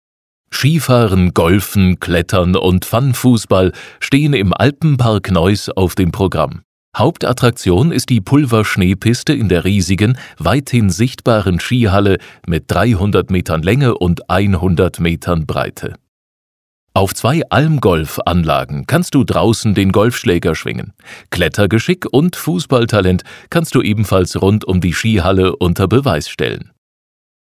audioguide-der-skihalle-neuss.mp3